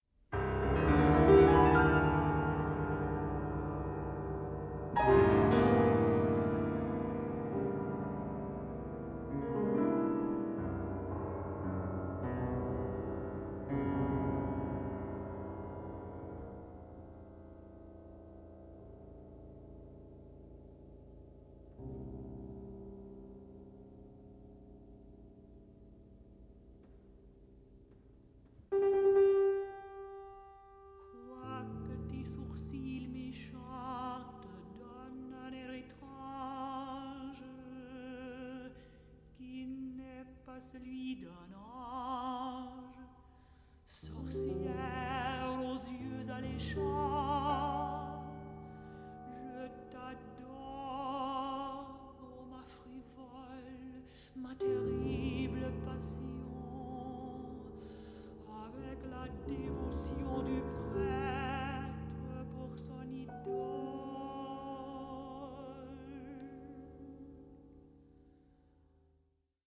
mezzo-soprano
piano